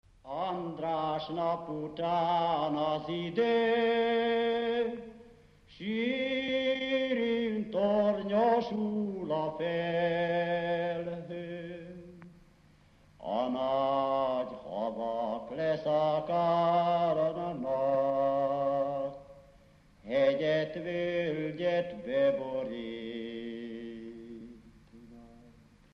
Felföld - Abaúj-Torna vm. - Cekeháza
ének
Stílus: 1.2. Ereszkedő pásztordalok
Szótagszám: 8.8.8.8
Kadencia: 8 (5) b3 1